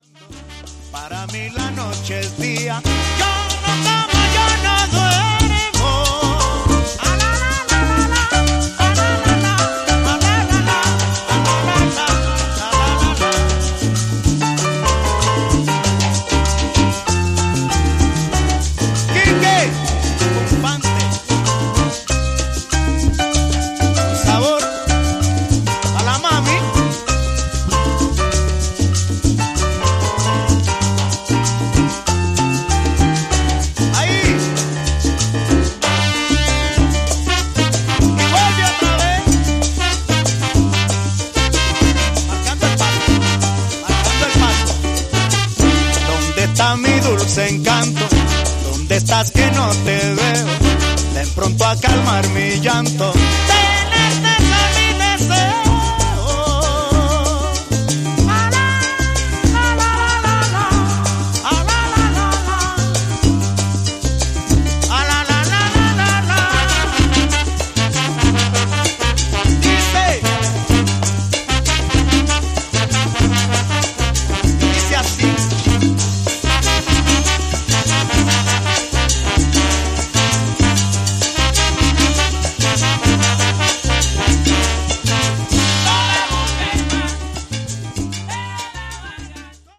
brings a slower, more melodic touch without losing any punch
His voice is raw, expressive, and instantly recognisable.